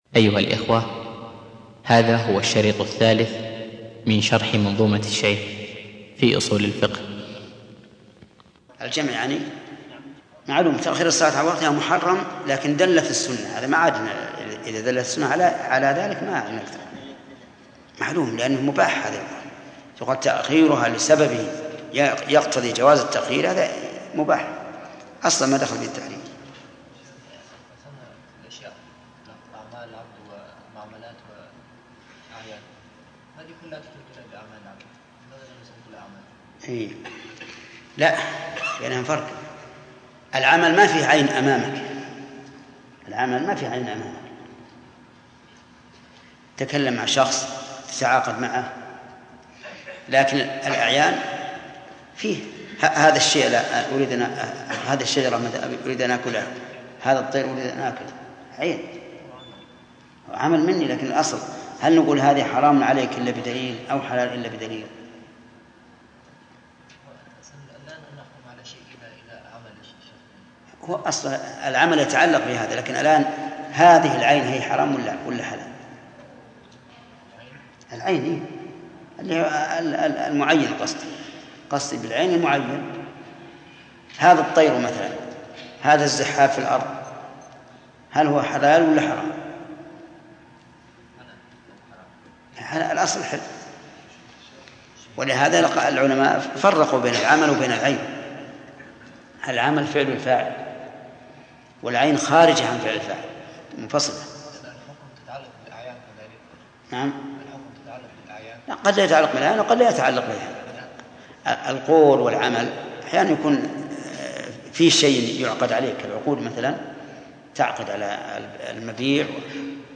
الدرس الثالث - المكتبة الإسلامية
شرح كتاب المنظومة في أصو ل الفقه و قواعده - الشيخ: محمد بن صالح العثيمين